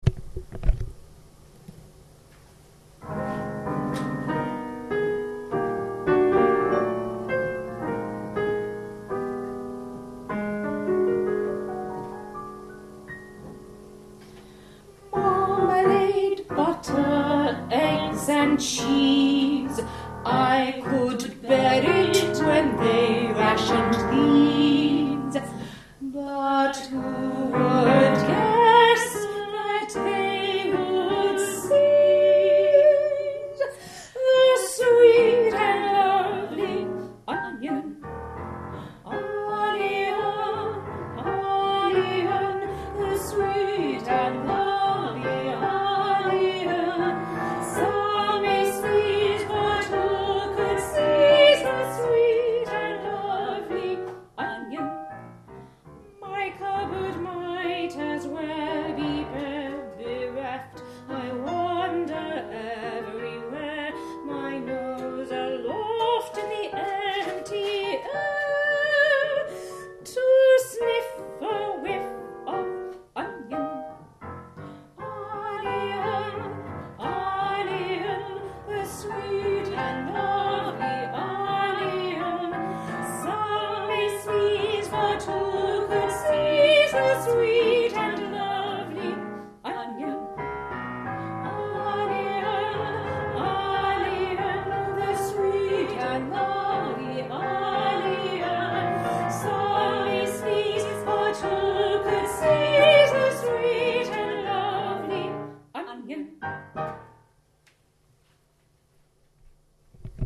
Vocal & Piano Audio of Onion Song